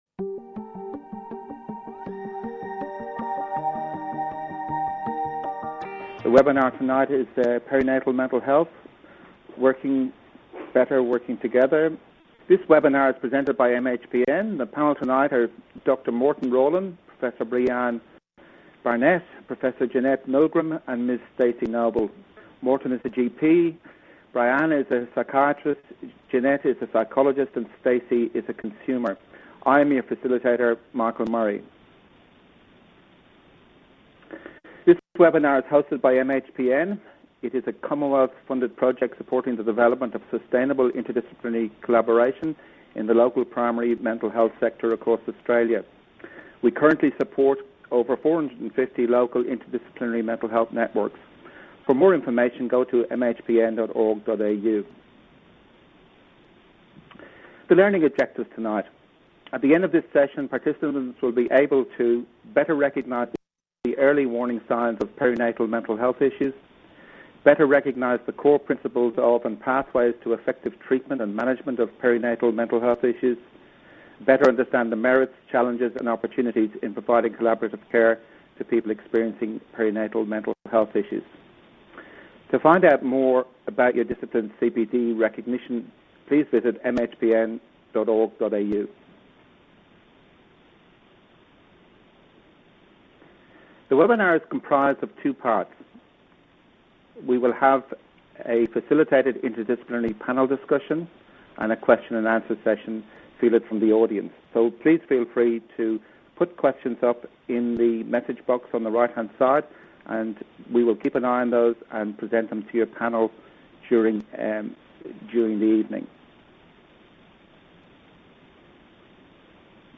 Q & A